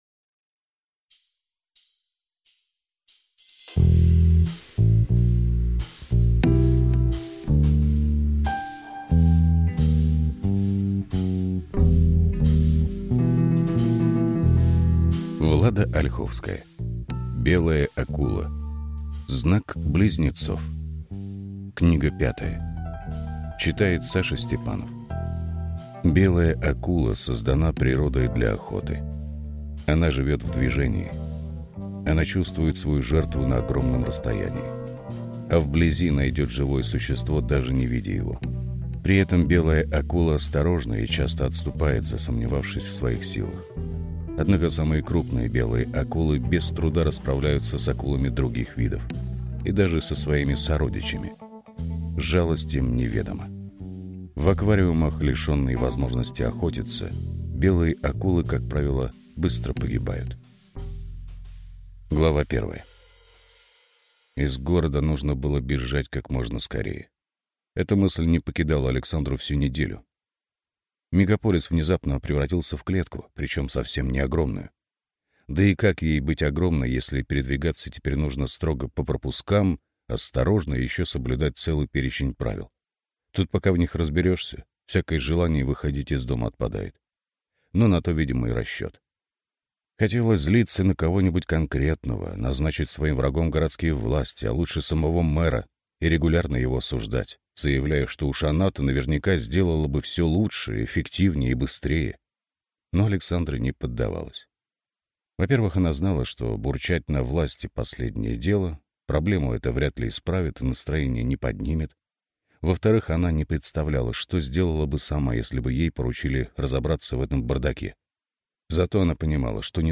Aудиокнига Белая акула